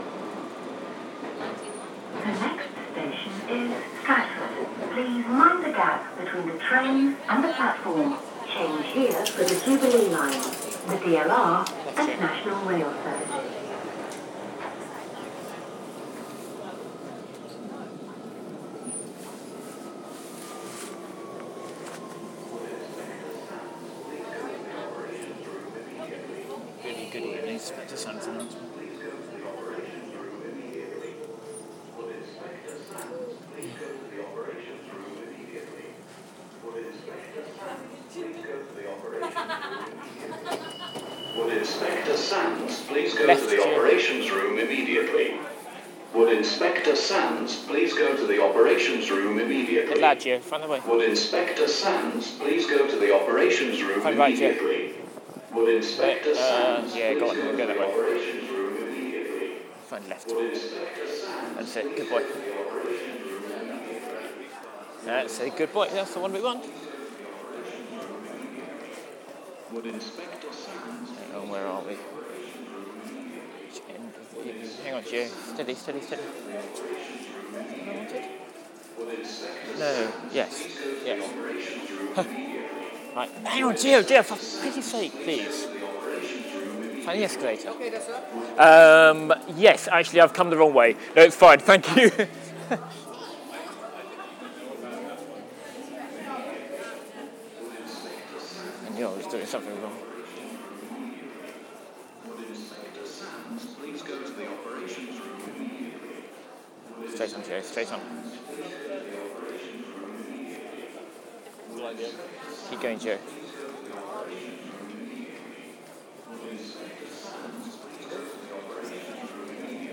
When heard at London stations and Inspector Sands announcement usually heralds certain doom, such as a fire evacuation or bomb scare. On this occasion however it seems somebody had simply fallen asleep on the button.